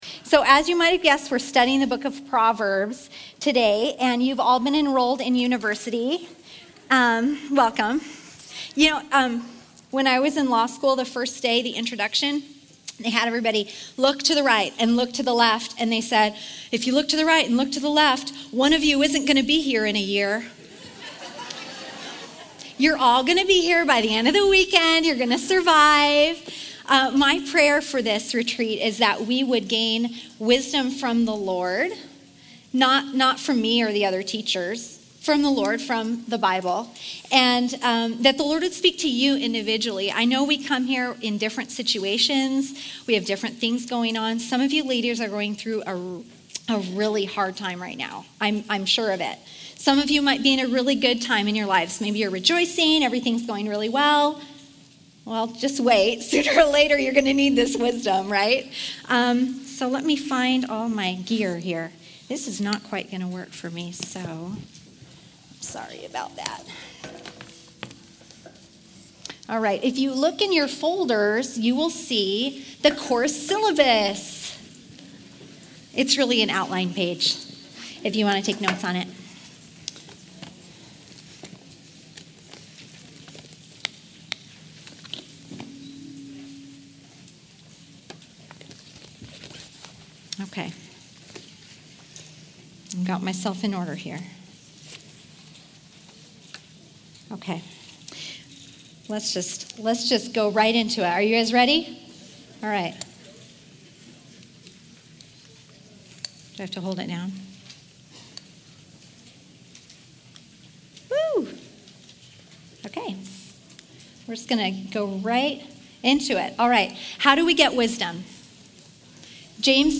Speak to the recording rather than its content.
Women's Retreat 2015: Pearl's of Wisdom